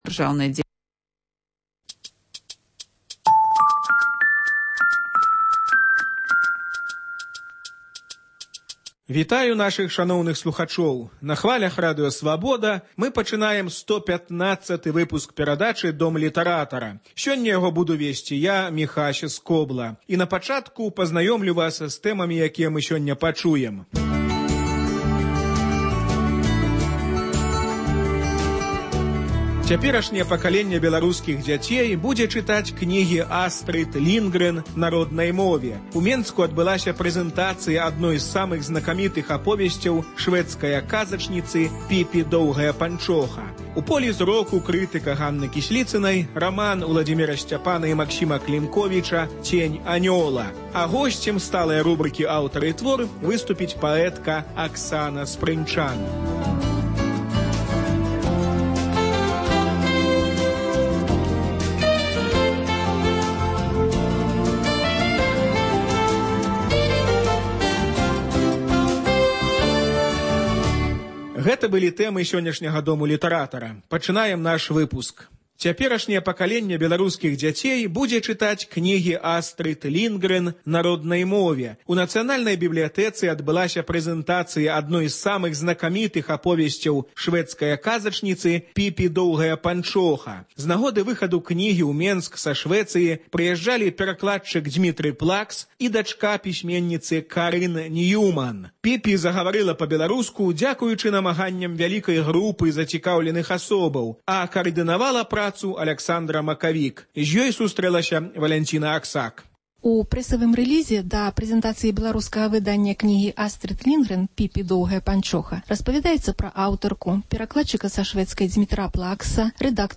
Літаратурны агляд